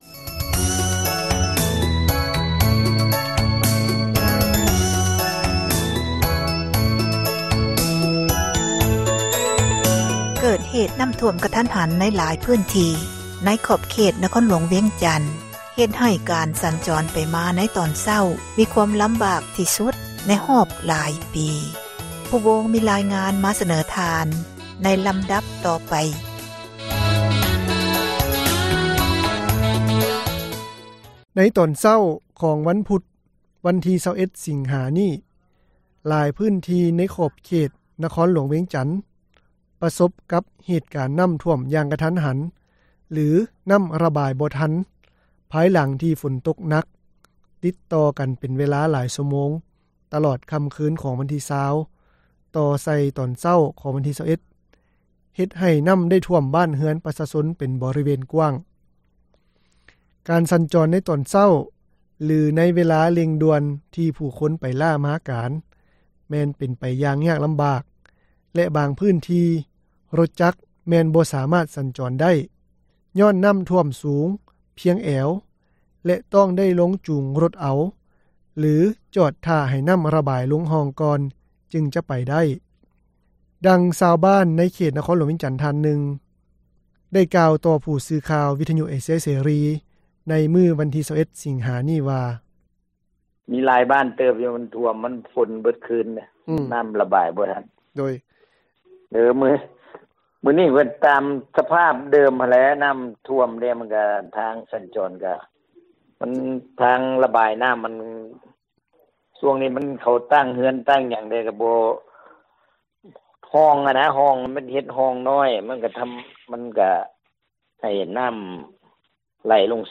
ດັ່ງຊາວບ້ານໃນເຂດນະຄອນຫຼວງວຽງຈັນ ທ່ານນຶ່ງ ໄດ້ກ່າວຕໍ່ຜູ້ສື່ຂ່າວວິທຍຸເອເຊັຽເສຣີ ໃນມື້ວັນທີ 21 ສິງຫາ ນີ້ວ່າ:
ພະນັກງານໃນຮ້ານຄ້າຂອງນັກລົງທຶນຈີນ ນາງນຶ່ງ ກໍໄດ້ກ່າວໃນທຳນອງດຽວກັນວ່າ ນ້ຳໄດ້ຖ້ວມແຮງຫຼາຍ ເຮັດໃຫ້ເຄື່ອງຂອງໃນຮ້ານ ເສັຽຫາຍ ຈຳນວນນຶ່ງ ແລະ ການສັນຈອນເປັນໄປດ້ວຍຄວາມຫຍຸ້ງຍາກ ຕລອດຕອນເຊົ້າ.
ເຊັ່ນດຽວກັນກັບອາສາສມັກກູ້ພັຍທ່ານນຶ່ງ ກໍໄດ້ກ່າວຕໍ່ຜູ້ສື່ຂ່າວວິທຍຸເອເຊັຽເສຣີ ໃນມື້ດຽວກັນນີ້ວ່າ ນ້ຳຖ້ວມຂັງມື້ເຊົ້ານີ້ ຖືວ່າຮ້າຍແຮງຫຼາຍ ທີ່ສຸດ ໃນຮອບຫຼາຍປີ.